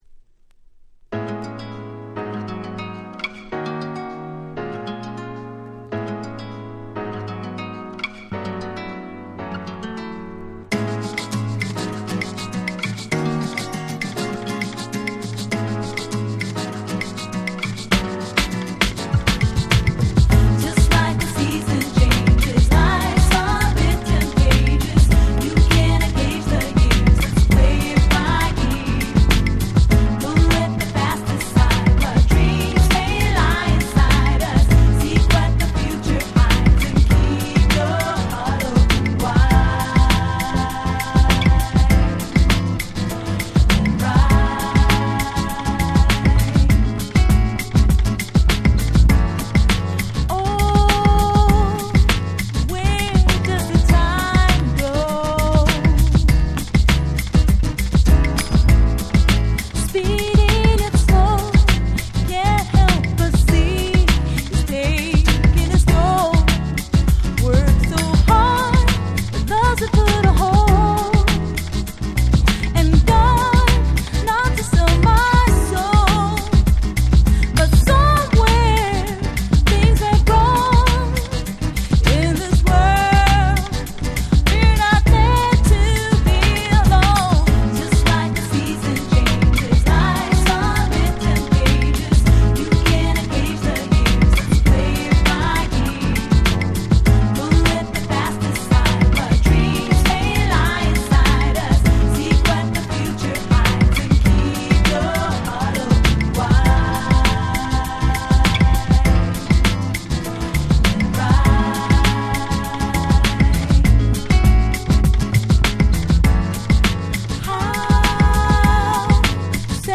99' Very Nice Japanese R&B !!
90's J-R&B